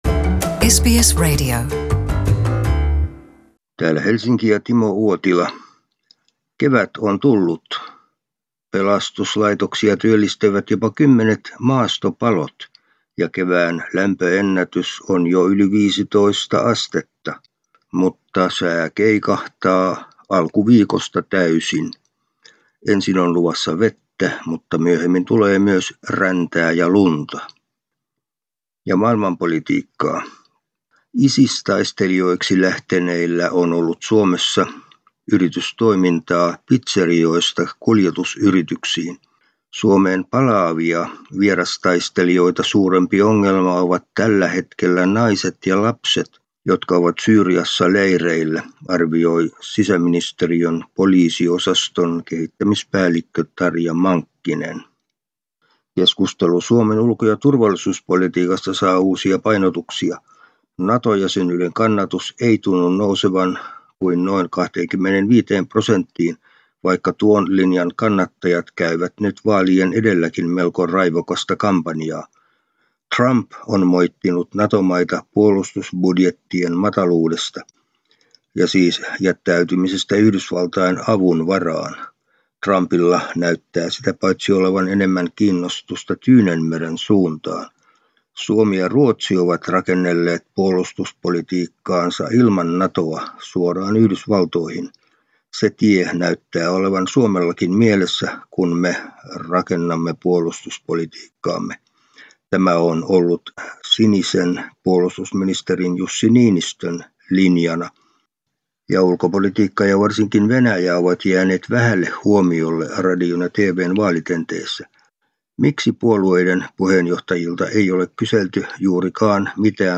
ajakohtaisraportti Suomesta